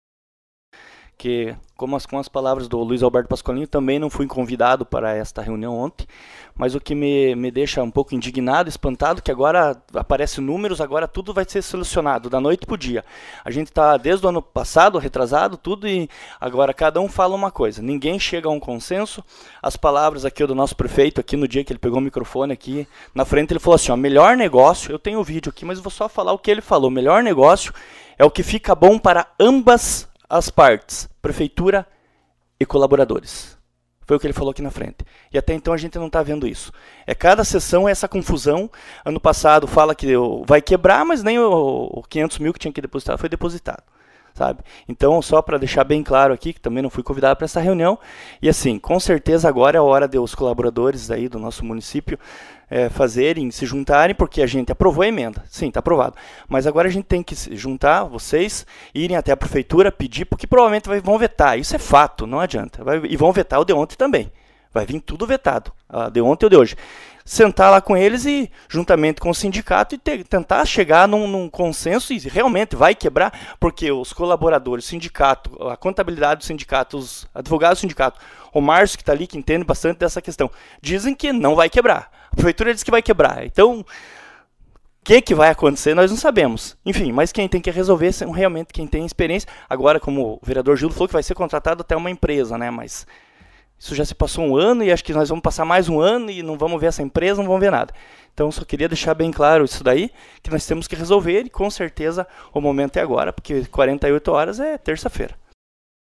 Sonora Fernando Barulho